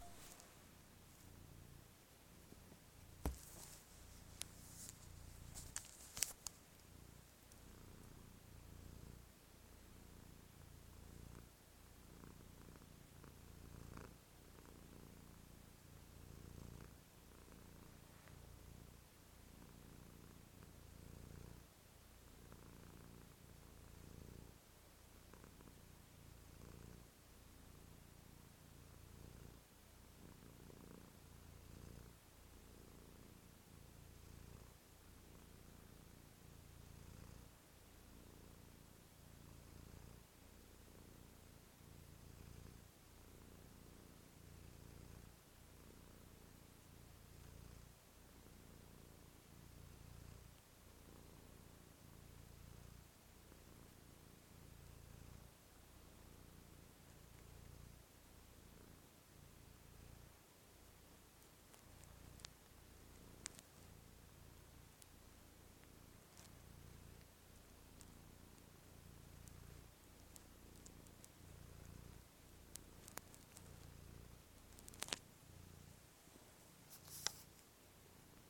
schnurrender